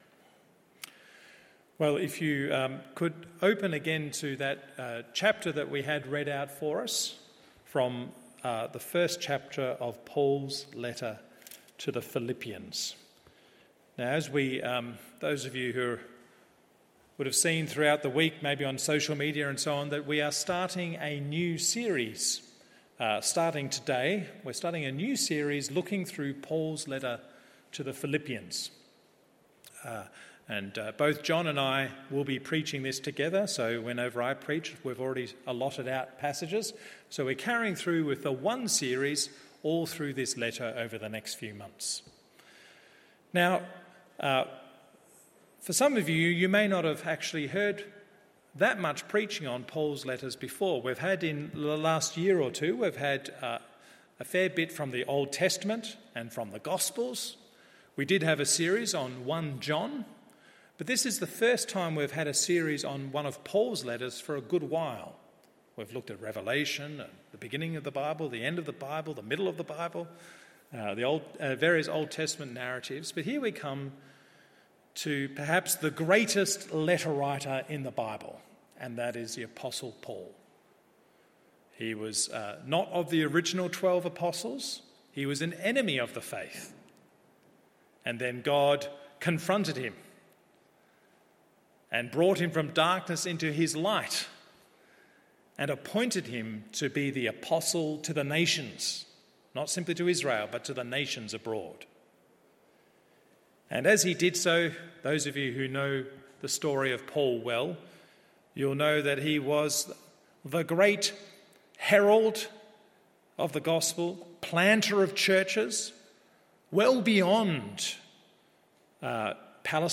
MORNING SERVICE Philippians 1…